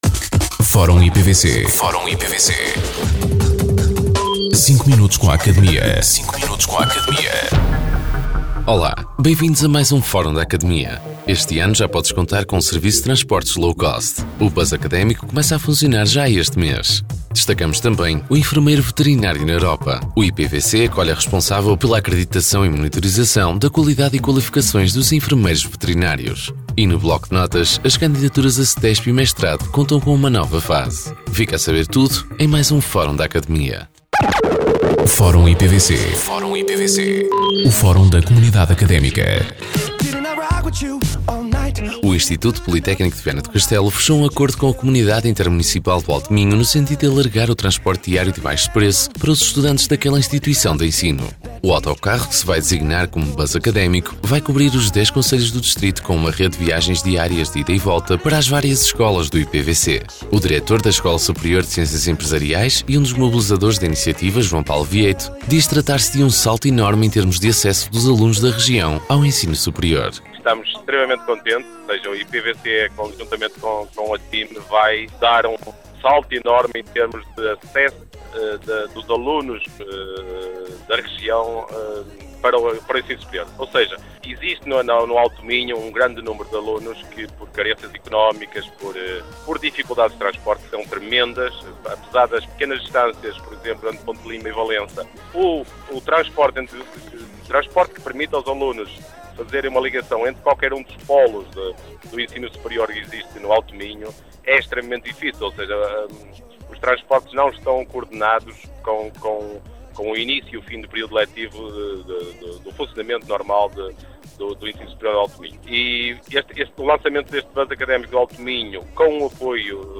Entrevistados: